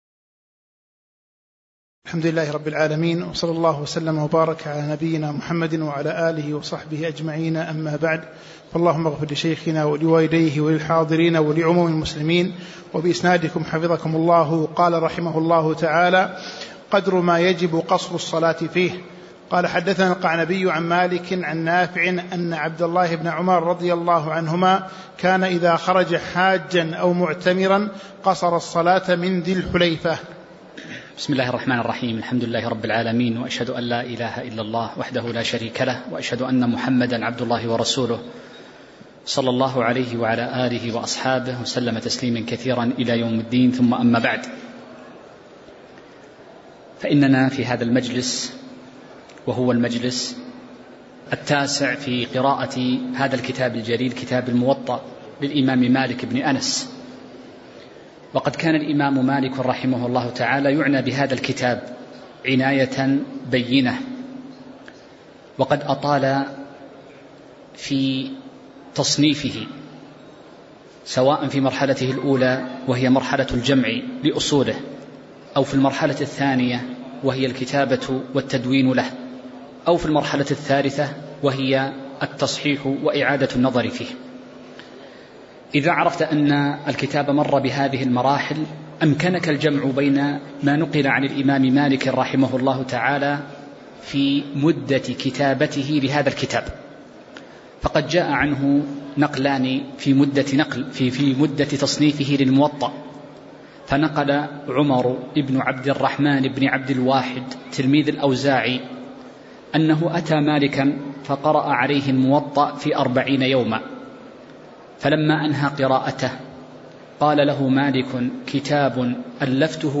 تاريخ النشر ٢٤ شوال ١٤٤٥ هـ المكان: المسجد النبوي الشيخ